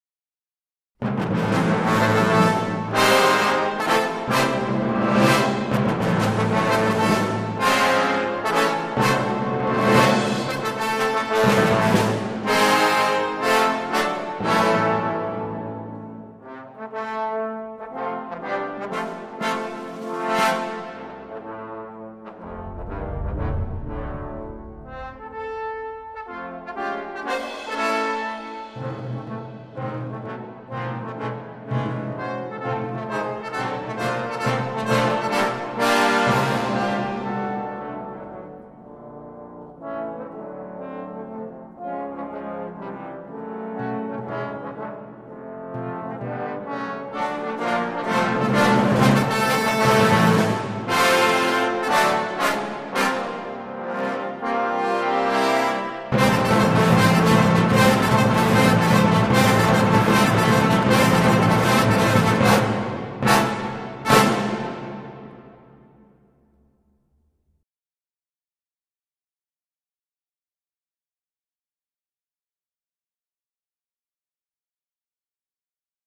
本辑的十六位长号演奏家分别来自伦敦七大乐团，
其强烈的管乐声与打击乐器即深深震慑人心。